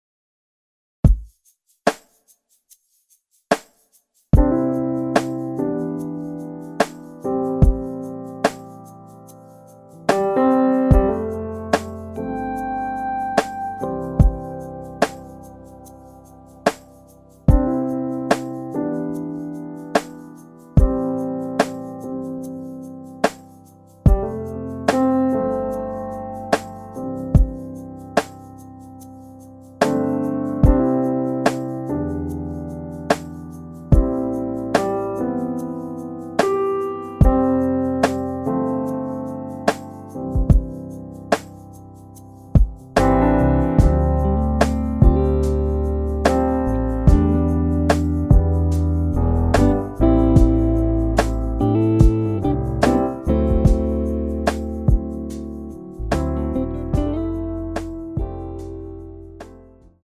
[공식 음원 MR] 입니다.
앞부분30초, 뒷부분30초씩 편집해서 올려 드리고 있습니다.
중간에 음이 끈어지고 다시 나오는 이유는